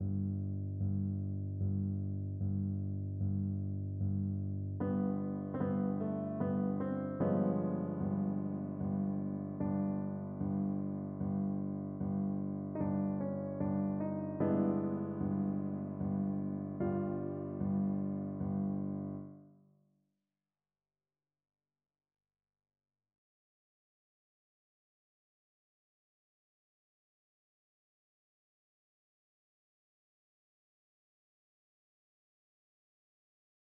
베토벤 피아노 소나타 11번의 아다지오 (2악장), 31–33마디.